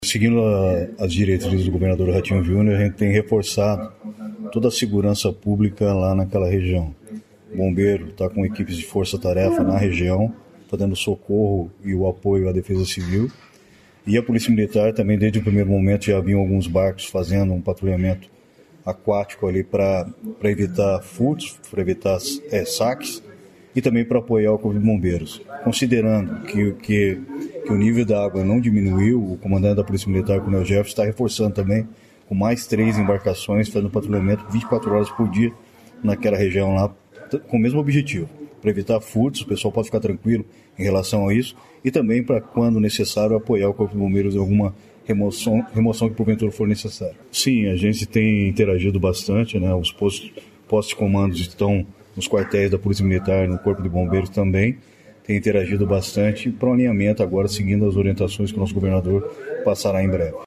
Sonora do secretário Estadual de Segurança Pública, Hudson Teixeira, sobre o envio de efetivo para ajuda às vitimas das chuvas no Paraná